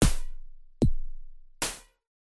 The two "sides" provide different parts of the instrument: one half provides the impact when the drum starts, and the other half provides the trailing noise.
In the audio file below we can hear the individual components making up the FM snare drum (in play order: the full drum, the impact, and the noise).
Audio: FM snare drum analysis
fm-snare-analysis.mp3